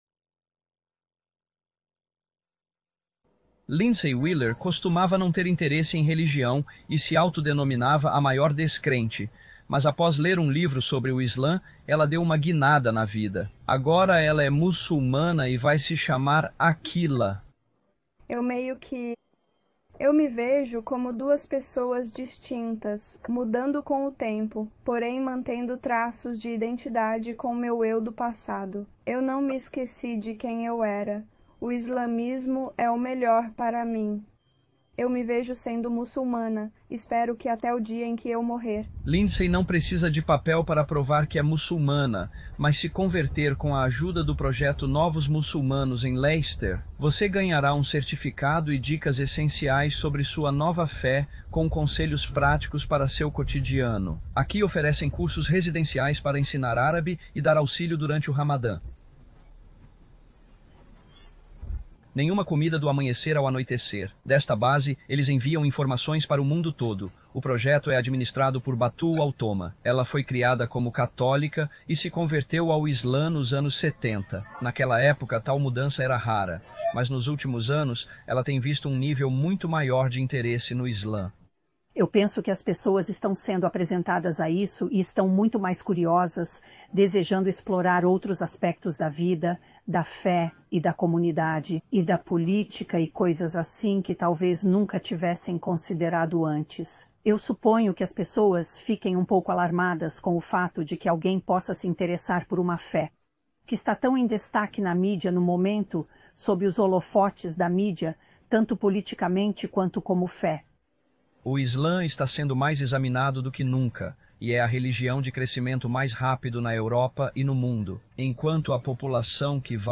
Descrição: Neste noticiário da Five News, os repórteres explicam como o Islã se tornou a religião que mais cresce no Reino Unido e na Europa em geral.